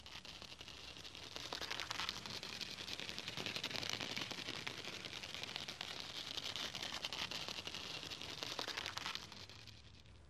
Звуки сколопендры - скачать и слушать онлайн бесплатно в mp3
Звук перебирающих ног сколопендры